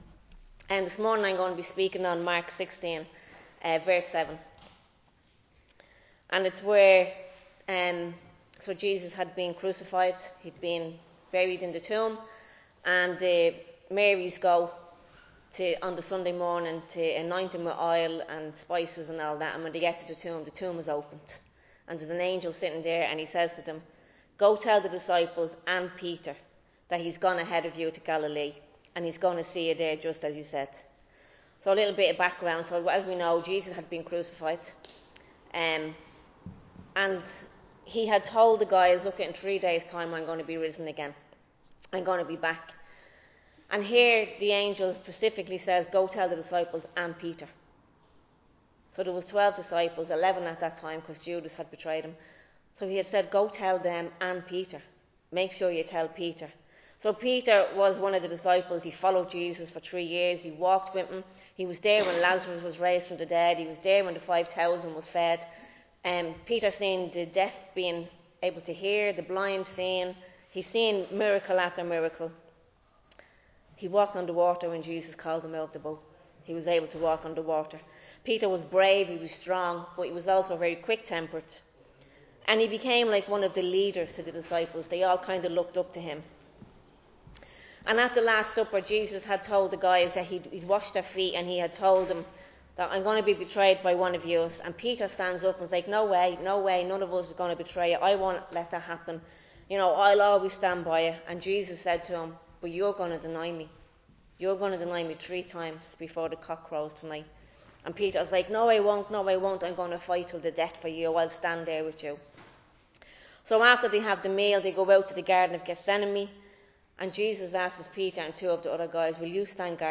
delivers a message on the Restoring Love of Jesus from Mark 16 Recorded live in Liberty Church on 19 October 2025